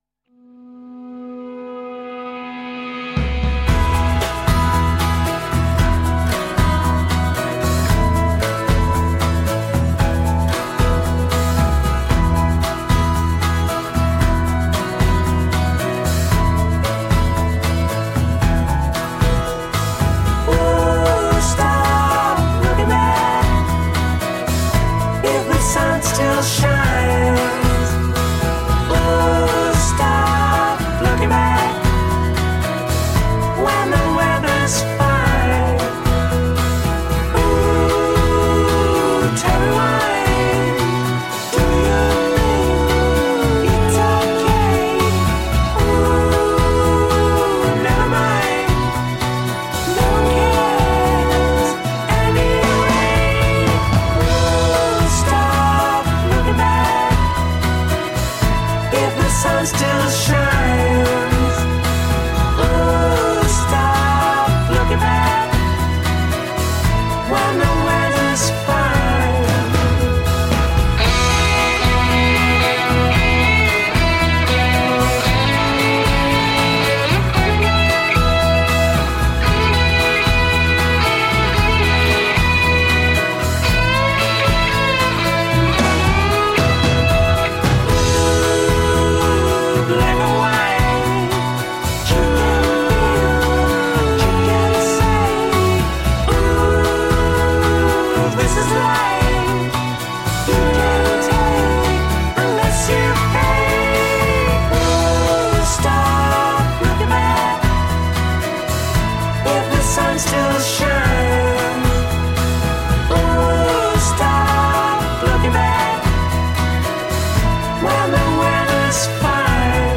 Tagged as: Alt Rock, Pop, Classic rock, Prog Rock